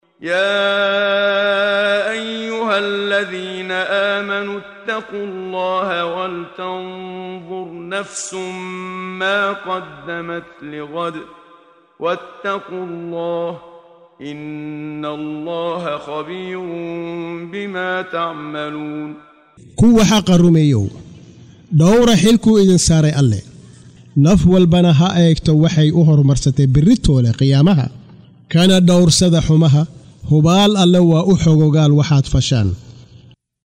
Waa Akhrin Codeed Af Soomaali ah ee Macaanida Suuradda Al-Xashar ( Kulminta ) oo u kala Qaybsan Aayado ahaan ayna la Socoto Akhrinta Qaariga Sheekh Muxammad Siddiiq Al-Manshaawi.